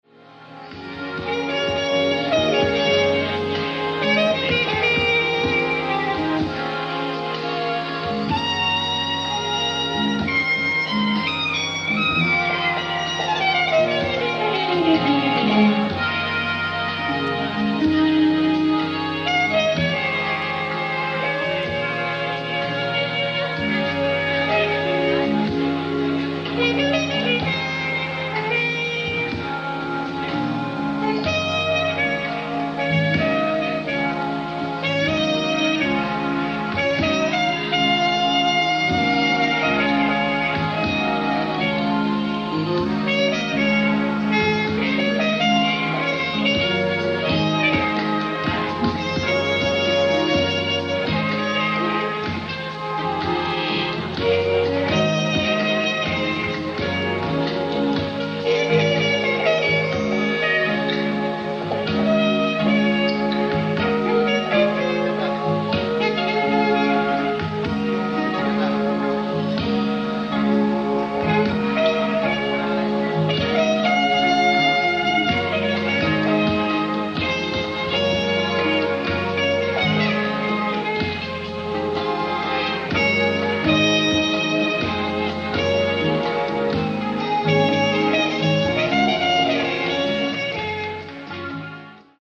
ライブ・アット・ジャス・サミット、ヴィーゼン、オーストリア 07/07/1984
※試聴用に実際より音質を落としています。
(Complete Alternate version)